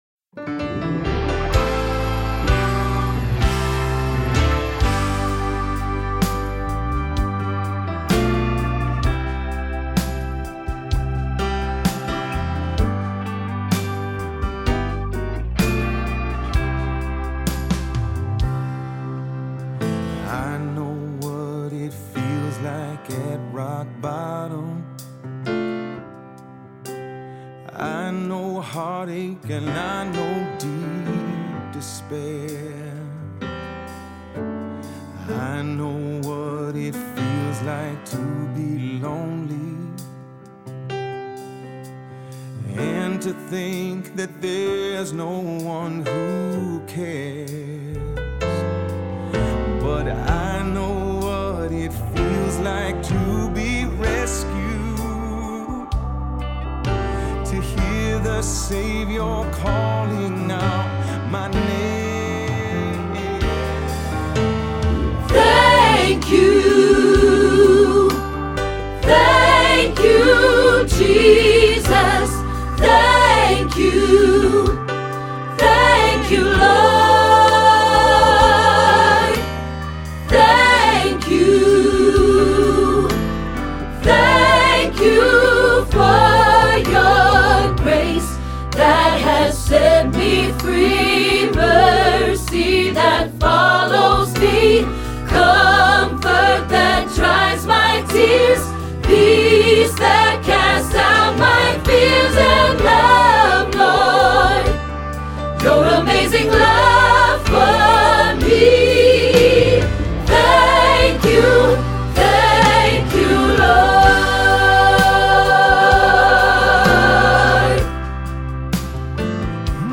Thank You, Lord – Soprano – Hilltop Choir
01-Thank-You-Lord-Soprano.mp3